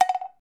bonus_sfx.mp3